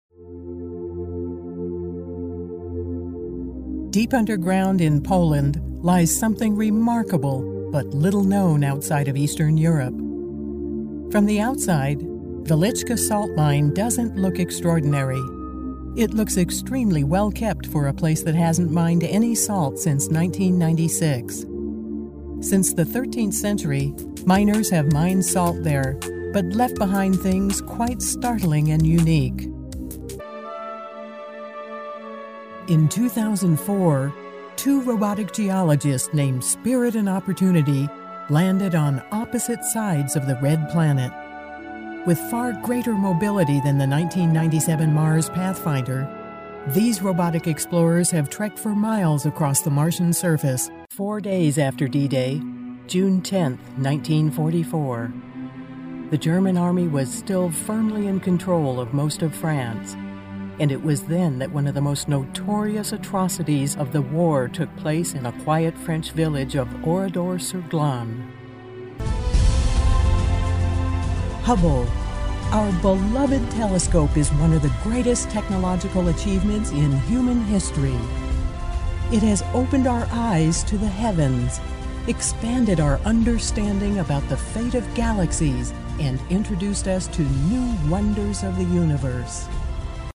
Sennheiser 416 mic, Blue Robbie preamp, Mogami cabling, ProTools 8.04 with various additional plug-ins, MBox mini, Adobe Audition, music and sound fx library.
Smooth, classy, believable.
Sprechprobe: Sonstiges (Muttersprache):